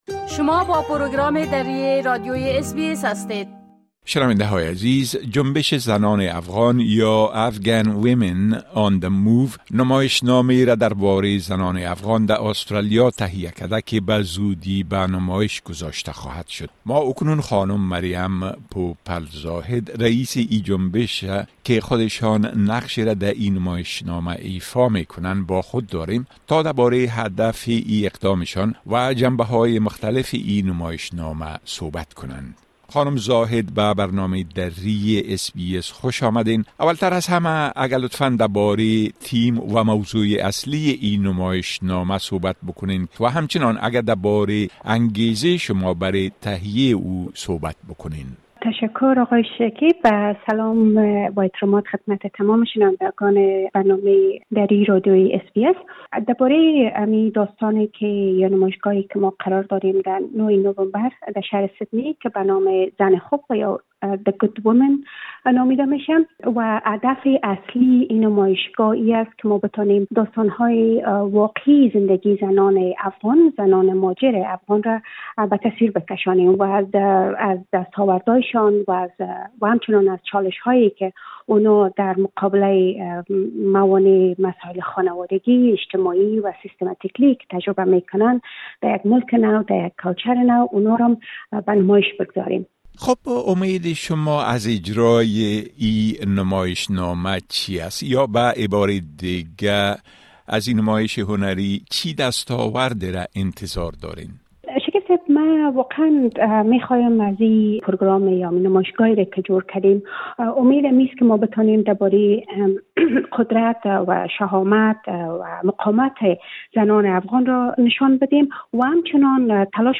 گفتگوی انجام دادیم.